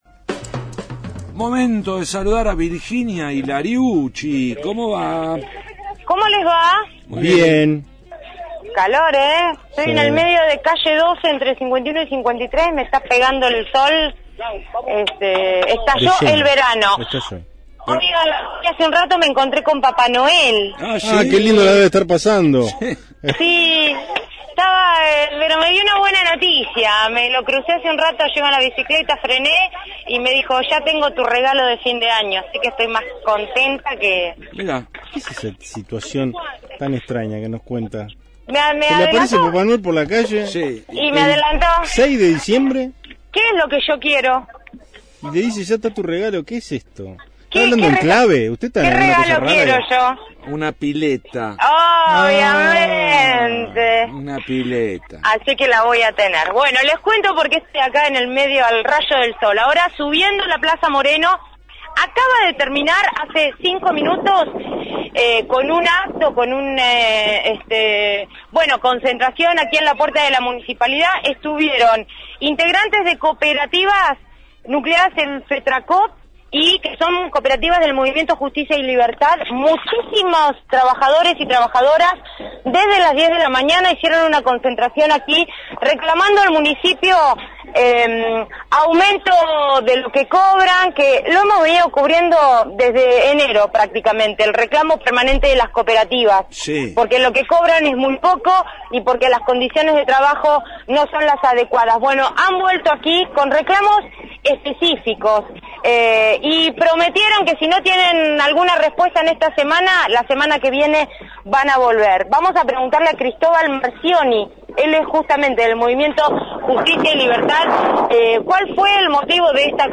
desde la Municipalidad de La Plata